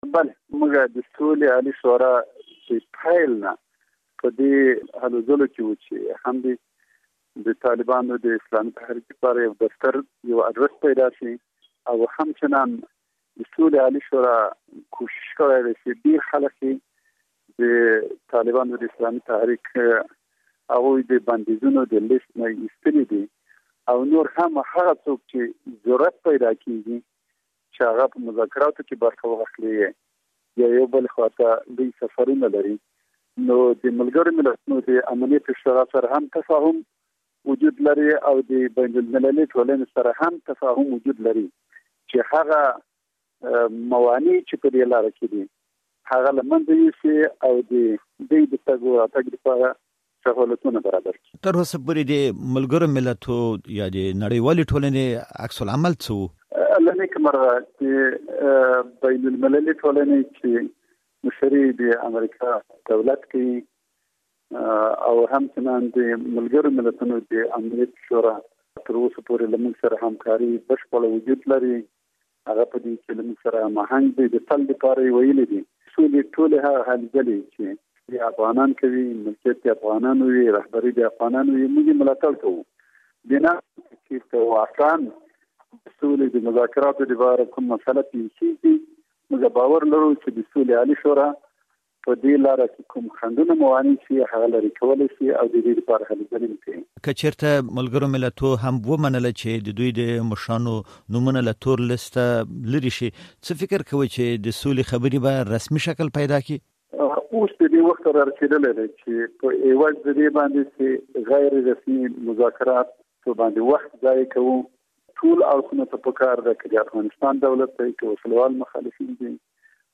مرکه
له عبدالحکيم مجاهد سره مرکه